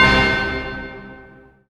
SI2 METAL04R.wav